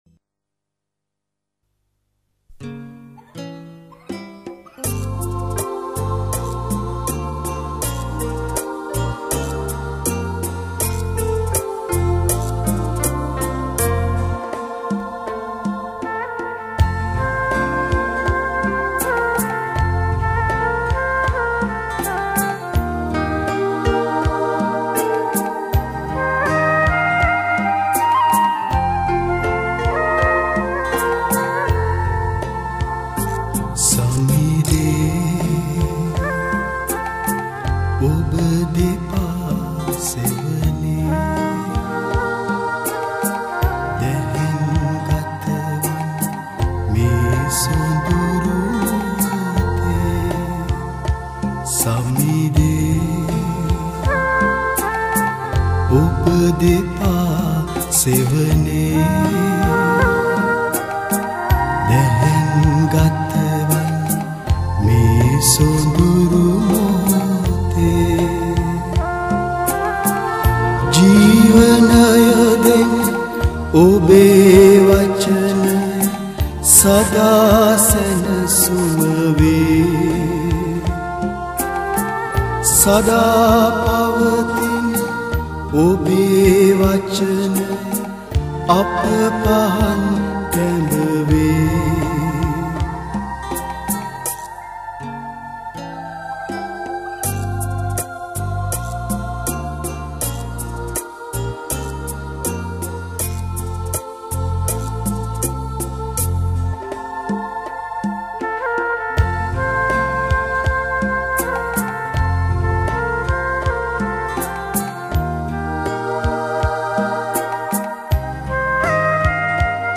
Sinhala Hymn Mp3 Download